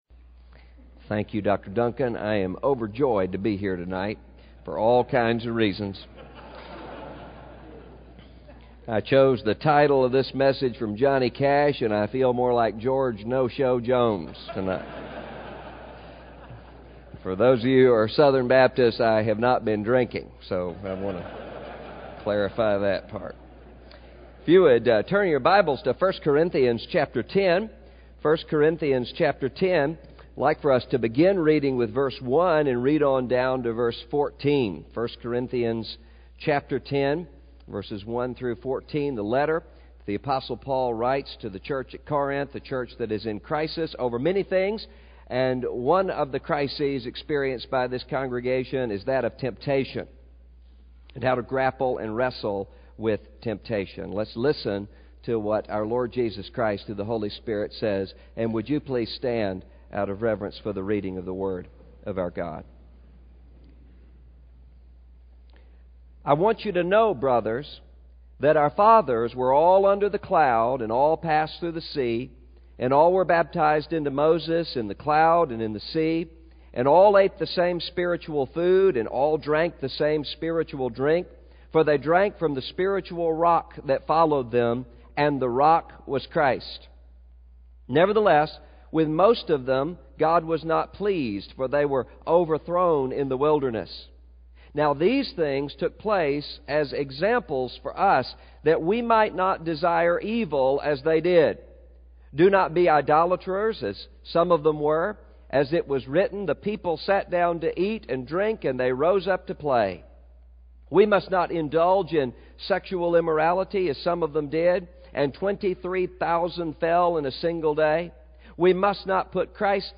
26_Annual_Mid_South_Mens_Rally_Session_2.mp3